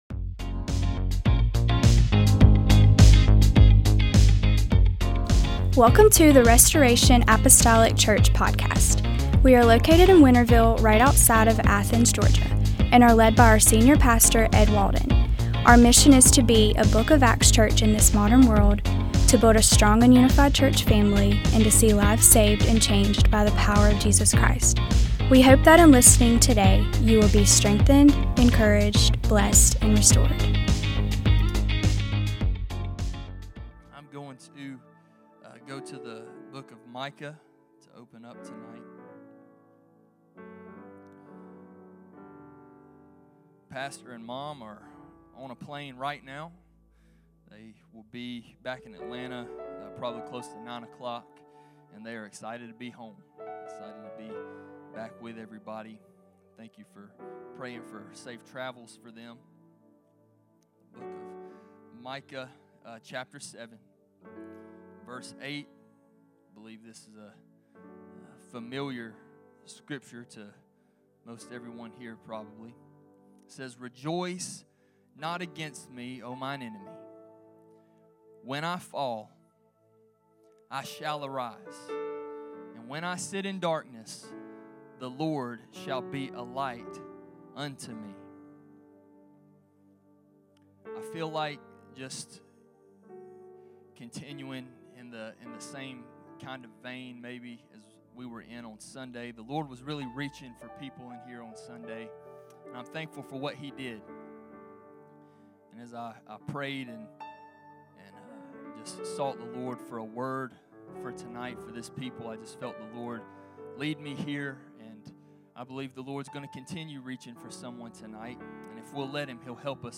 MDWK Service - 09/03/2025 - Assist.